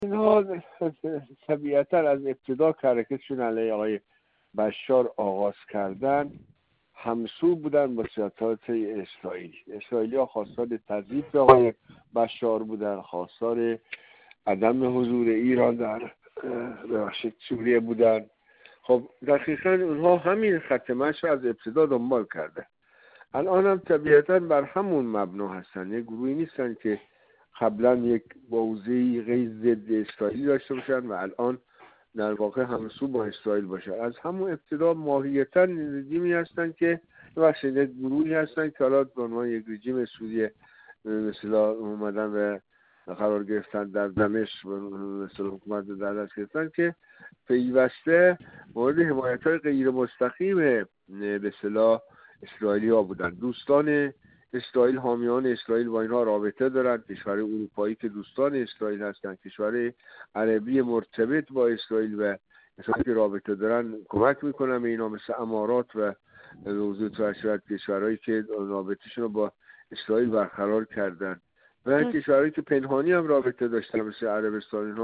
کارشناس مسائل غرب آسیا